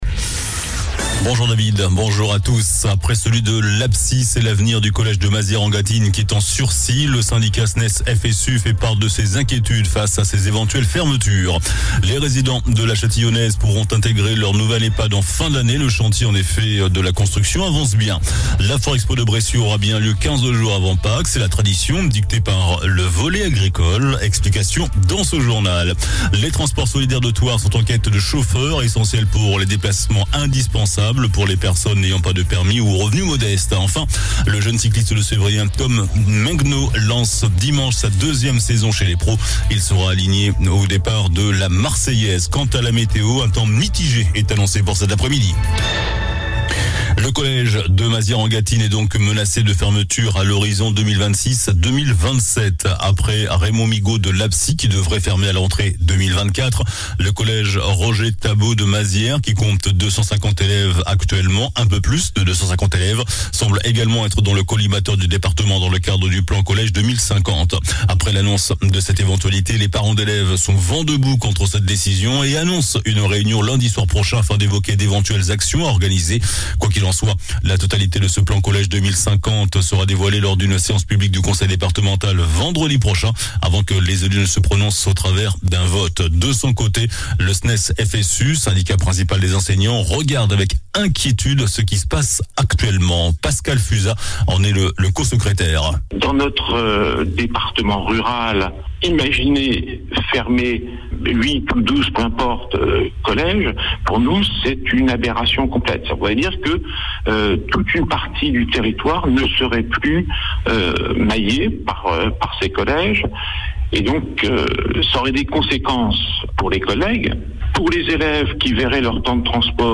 JOURNAL DU VENDREDI 27 JANVIER ( MIDI )